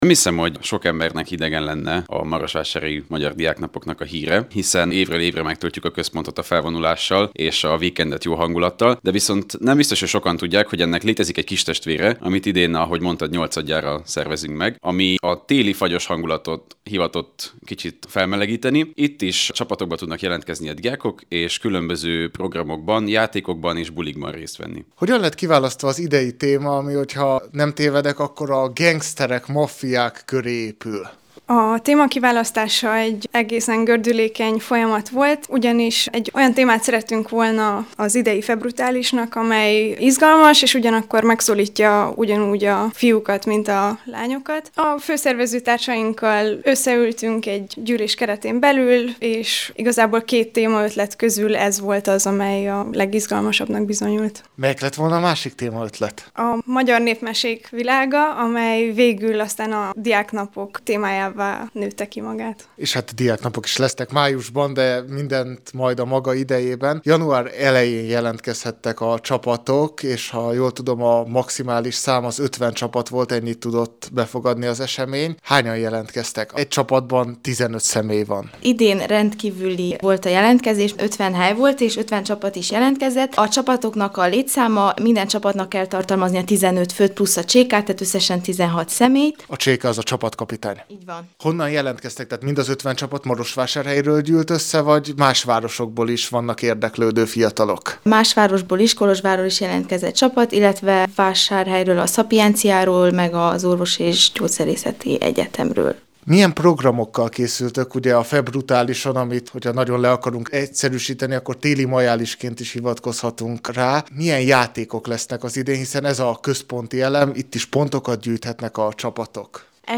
Az idei Febru(t)ális kapcsán beszélgettünk